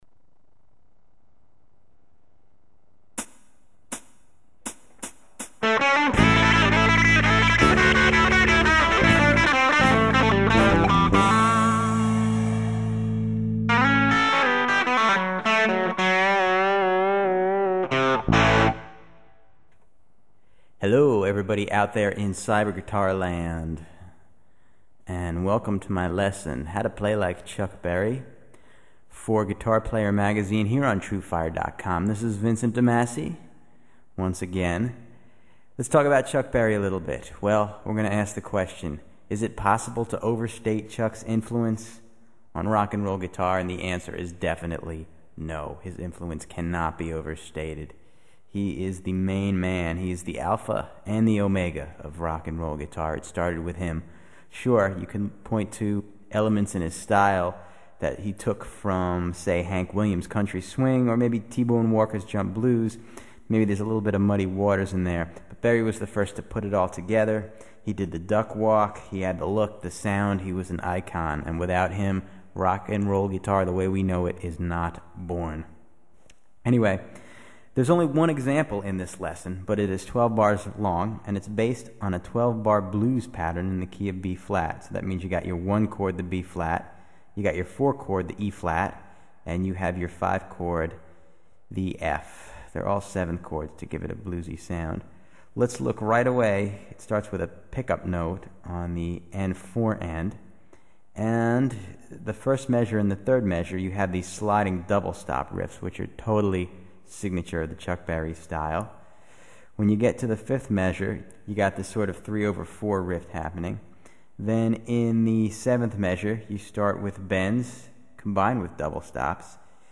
В первом кусочке небольшое гитарное вступление и пояснения автора.
Разговор